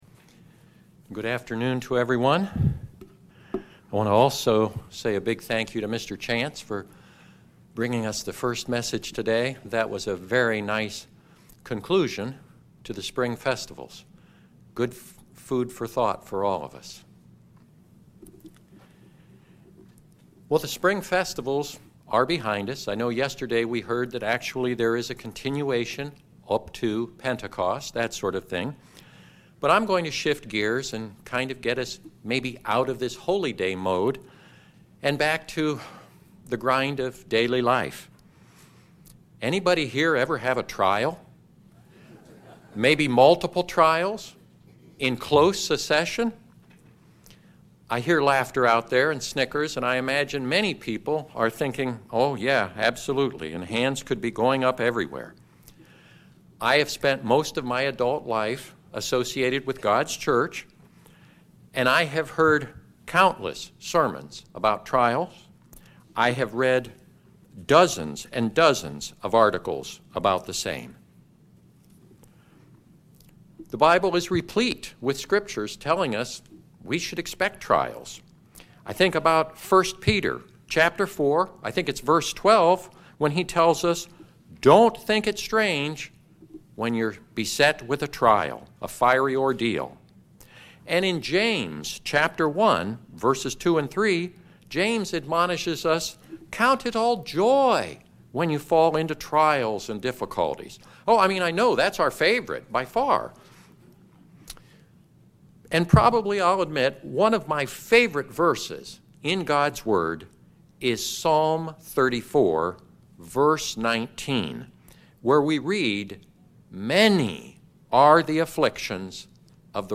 By analyzing Paul’s writings using English grammar rules, we gain a deeper appreciation for his burning passion for Christ and the Kingdom of God. This sermon will improve your English grammar skills as well as impress upon you the importance of pressing onward towards the kingdom.
Given in North Canton, OH